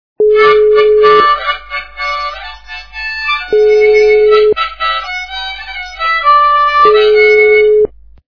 Габная гармошка - Звук губной гармошки Звук Звуки Звук - губної гармошки
При прослушивании Габная гармошка - Звук губной гармошки качество понижено и присутствуют гудки.